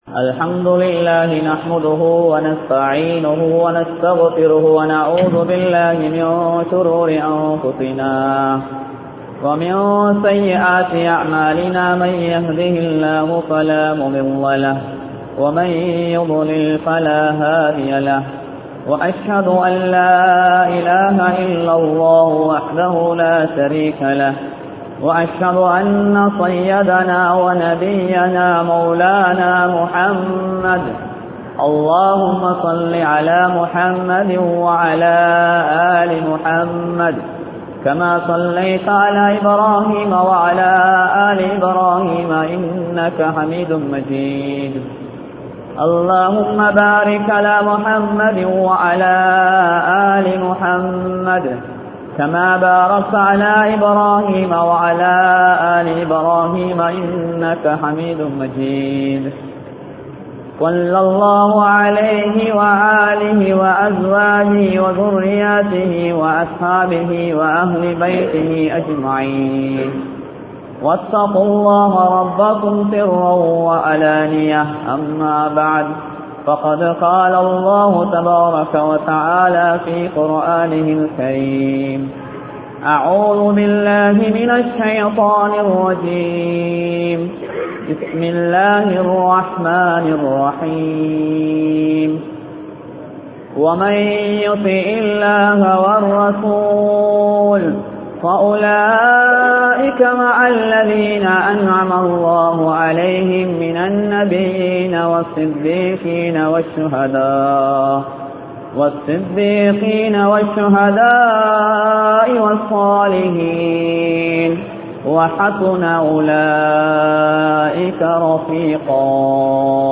Seitha Amalhalai Paathuhaapoam (செய்த அமல்களை பாதுகாப்போம்) | Audio Bayans | All Ceylon Muslim Youth Community | Addalaichenai
Kurunegala, Siyambalagaskottuwa Jumua Masjidh